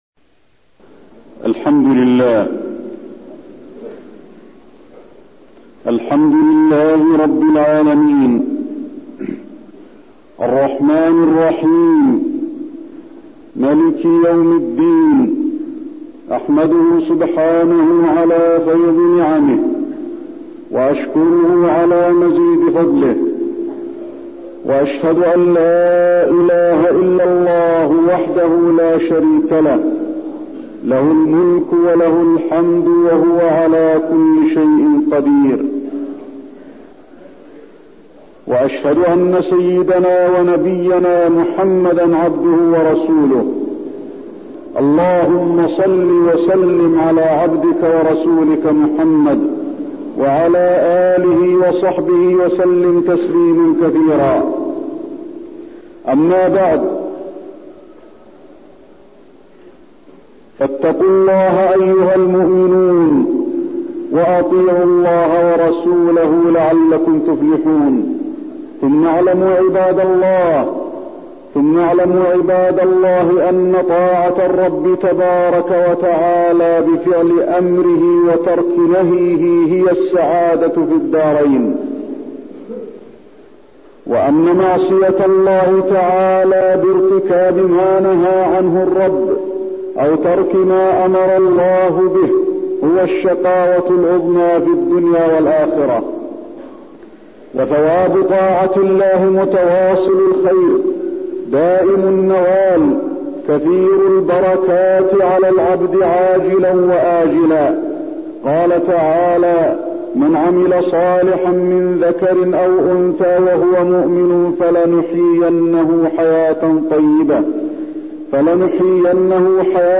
تاريخ النشر ١٣ رجب ١٤٠٧ هـ المكان: المسجد النبوي الشيخ: فضيلة الشيخ د. علي بن عبدالرحمن الحذيفي فضيلة الشيخ د. علي بن عبدالرحمن الحذيفي الحدود والكفارات أساس قيام الإسلام